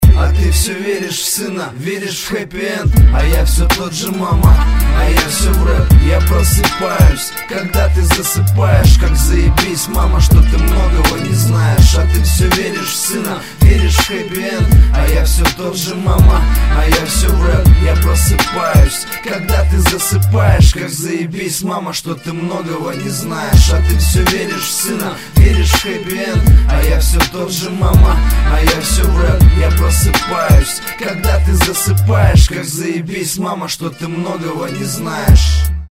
• Качество: 128, Stereo
русский рэп